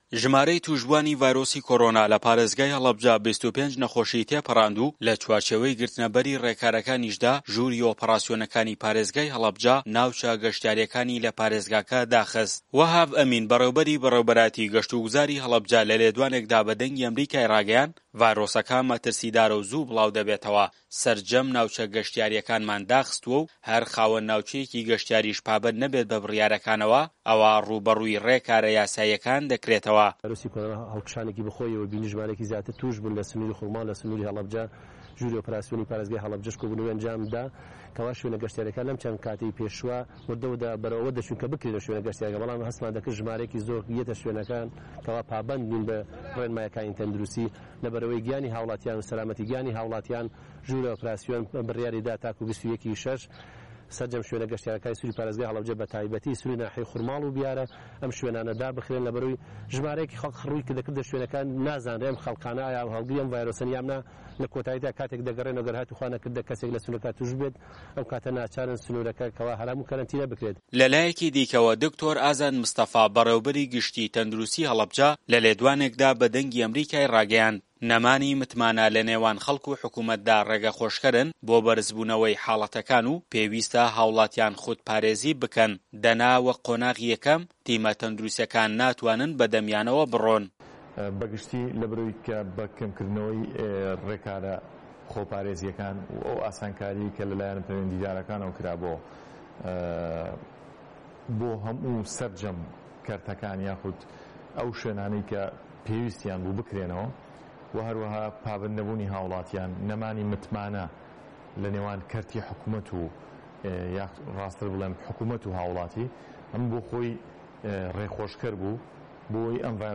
زانیاری زیاتر لە دەقی ڕاپۆرتەکەدایە